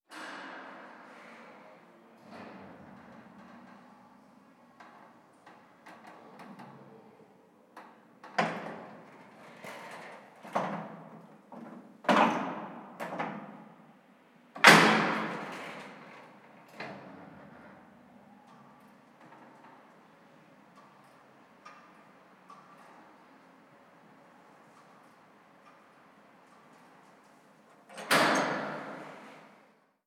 Ambiente de elevador industrial
elevador
Sonidos: Industria